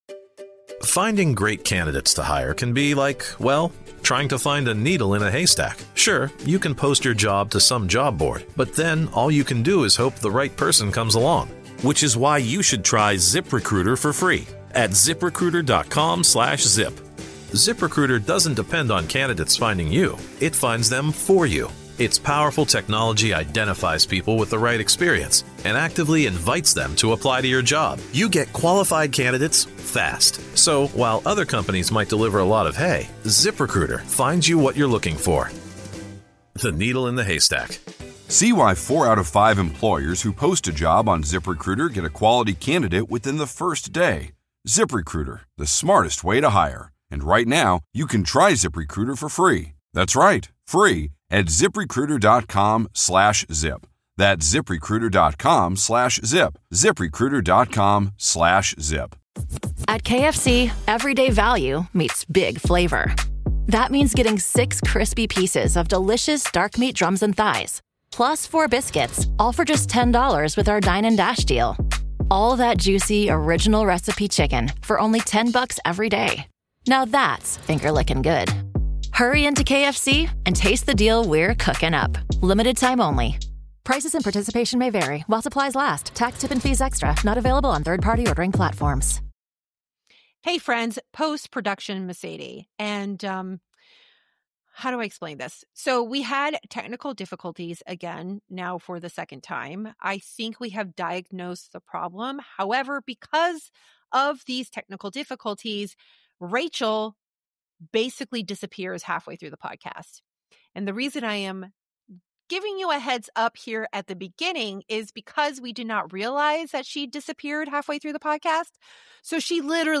We tackle the news, politics, and cultural battles the mainstream wants you to ignore, all from a perspective that values truth, freedom, and common sense. Delivered with wit, humor, and a whole lot of coffee, this is the podcast for anyone who refuses to be spoon-fed narratives and is ready to think critically about the world around them. Think of it as coffee with two friends who aren’t afraid to say what you’re already thinking.